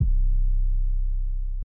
808 Bass.wav